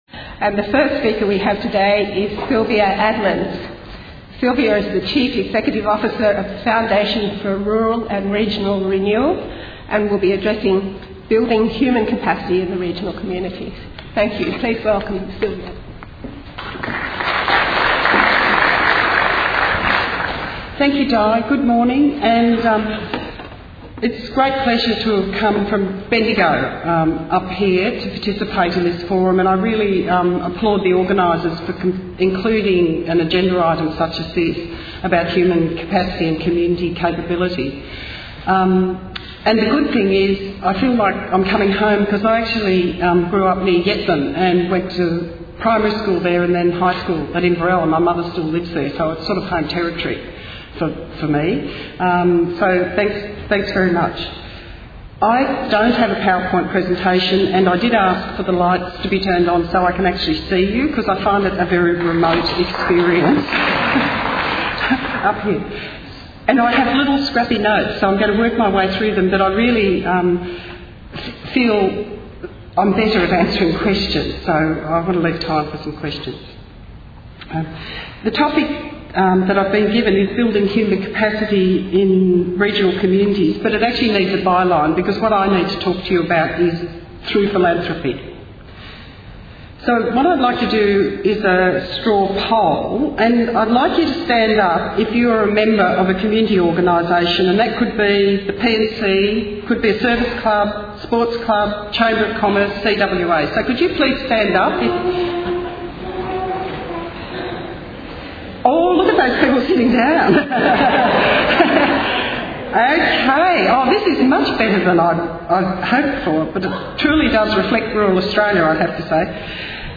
Building human capacity in regional communities- Sustaining Rural Communities Conference 2010 Presentation Audio